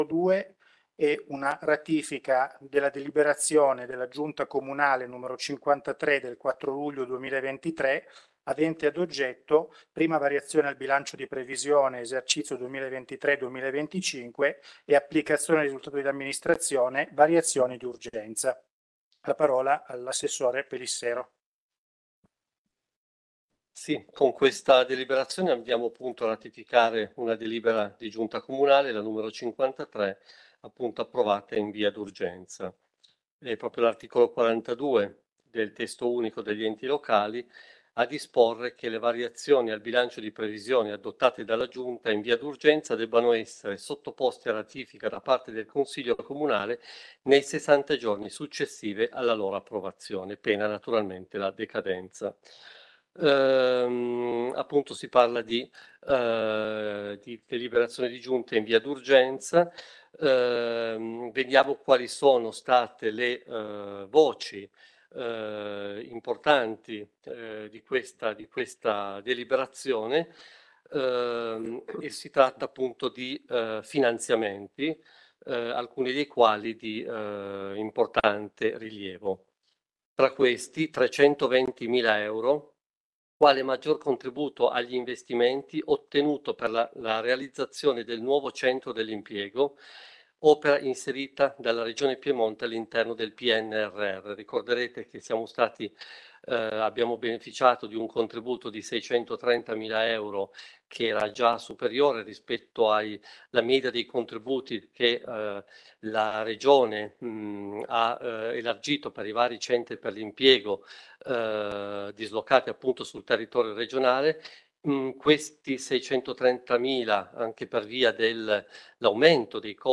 Consiglio Comunale Comune Susa 27.07.2023 PUNTO 2